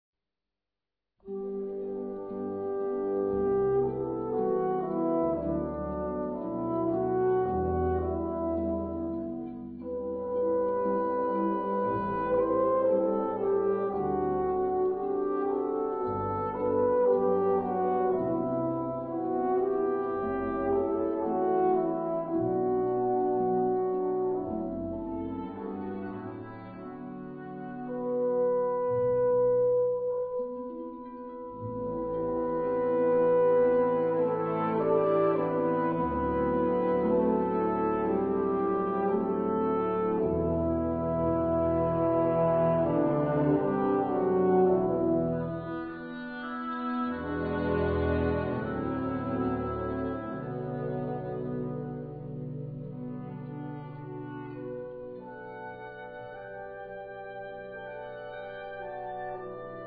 Gattung: Pavane
Besetzung: Blasorchester